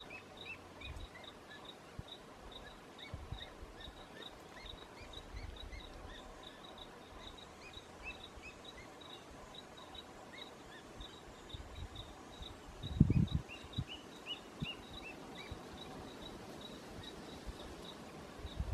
Tarefero (Sittasomus griseicapillus)
Nombre en inglés: Olivaceous Woodcreeper
Fase de la vida: Adulto
Localización detallada: Túneles de La Merced
Condición: Silvestre
Certeza: Vocalización Grabada